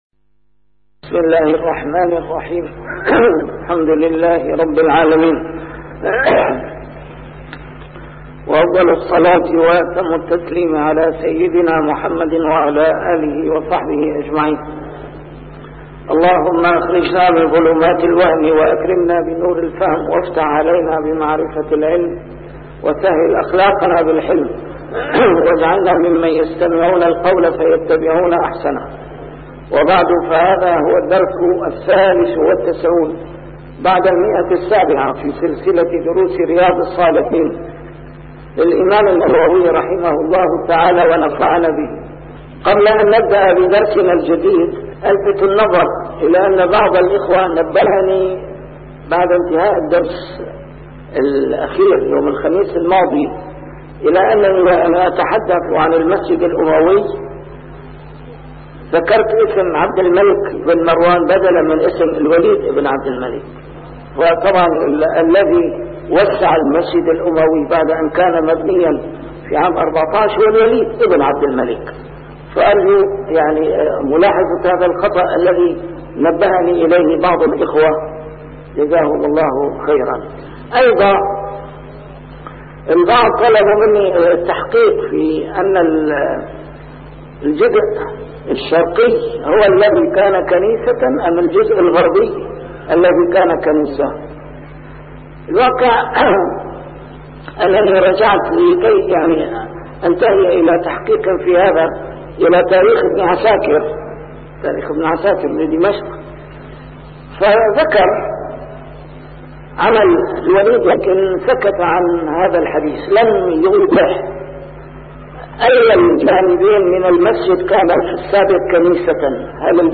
A MARTYR SCHOLAR: IMAM MUHAMMAD SAEED RAMADAN AL-BOUTI - الدروس العلمية - شرح كتاب رياض الصالحين - 793- شرح رياض الصالحين: يوم الجمعة